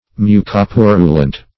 Search Result for " mucopurulent" : Wordnet 3.0 ADJECTIVE (1) 1. containing or composed of mucus and pus ; The Collaborative International Dictionary of English v.0.48: Mucopurulent \Mu`co*pu"ru*lent\, a. [Mucus + purulent.]